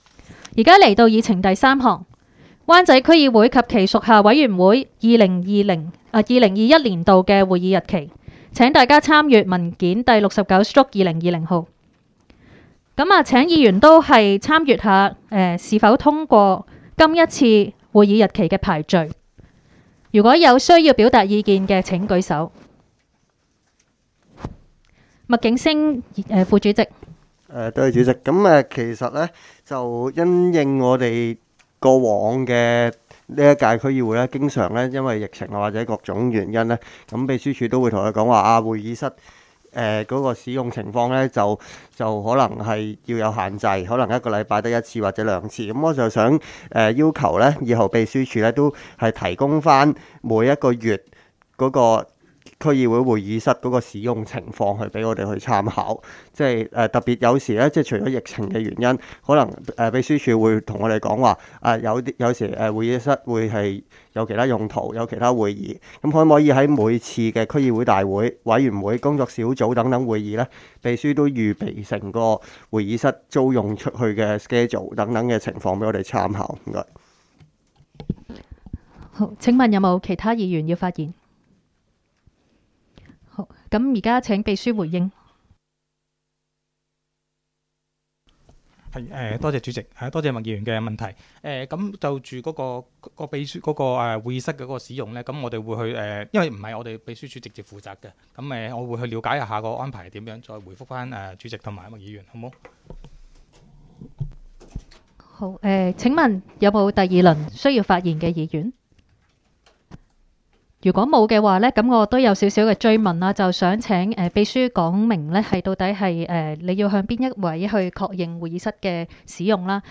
湾仔区议会会议室